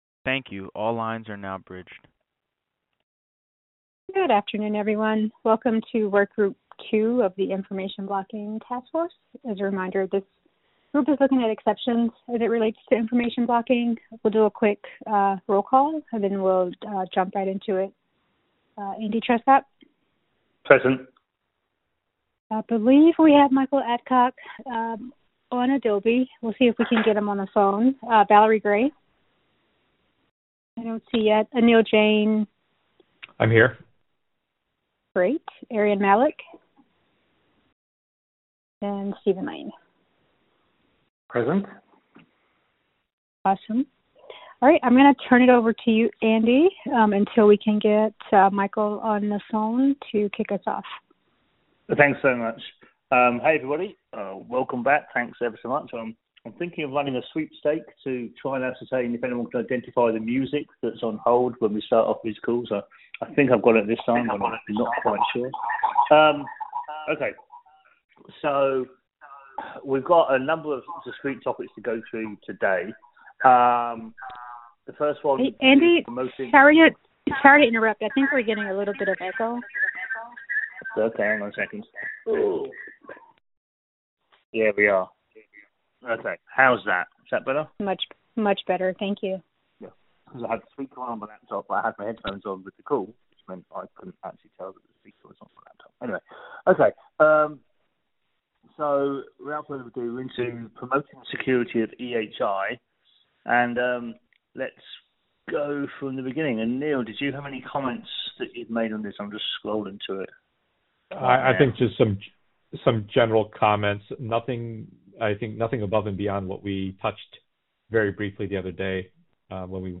2019-03-13_IACC_group2_VirtualMeeting_Audio